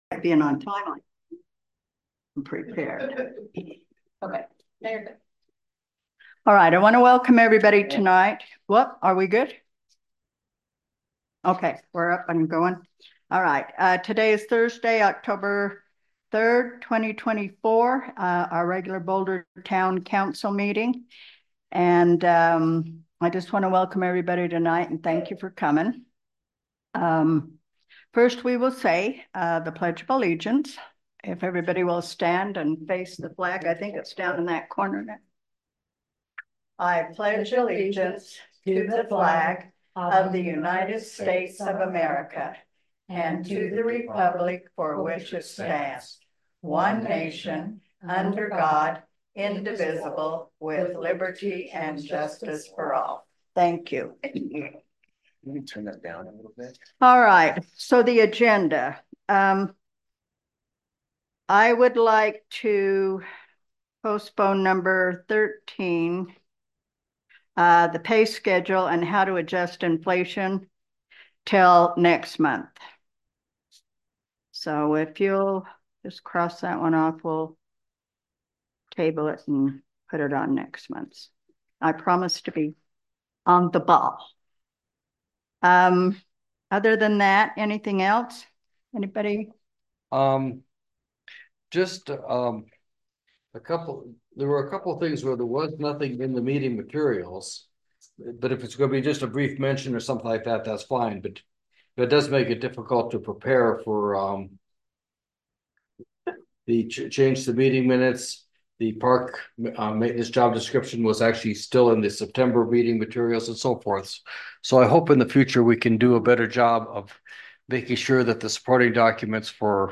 The Boulder Town Council will hold its regular meeting on Thursday October 3, 2024, starting at 7:00 pm at the Boulder Community Center Meeting Room, 351 No 100 East, Boulder, UT. Zoom connection will also be available.